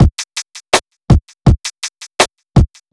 HP082BEAT3-R.wav